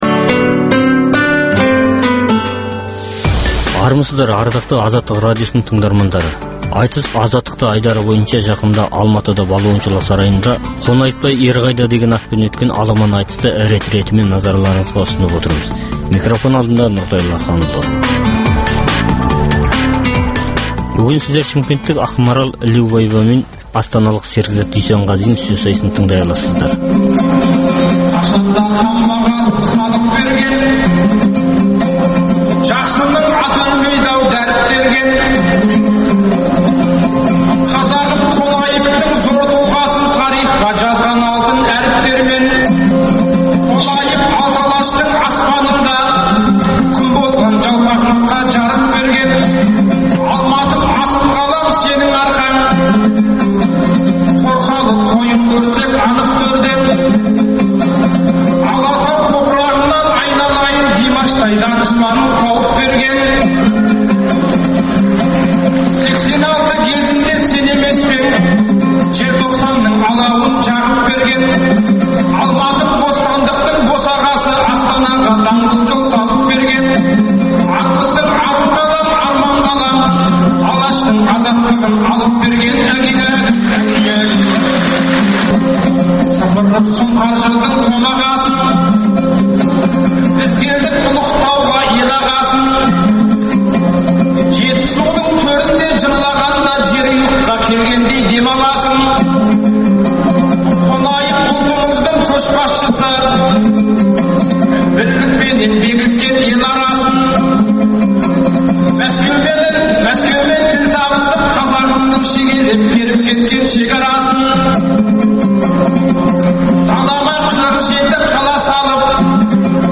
Айтыс - Азаттықта
Жақында Балуан Шолақ атындағы спорт сарайында өткен айтыстың алғашқы күні